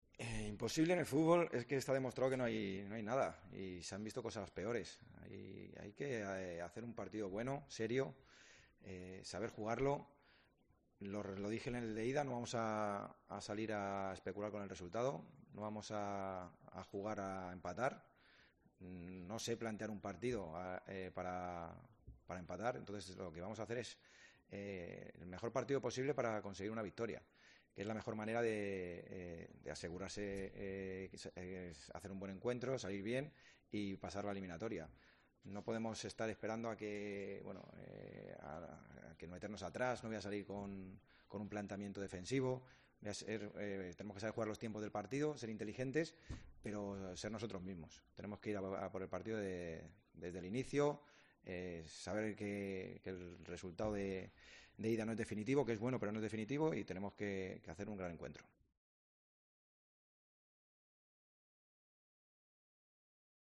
"Imposible no hay nada y se han visto cosas peores. Hay que saber jugar el partido. No vamos a jugar a empatar y lo que vamos a hacer es el mejor partido posible para conseguir una victoria”, dijo en la rueda de prensa previa al choque del miércoles en el Ciutat de València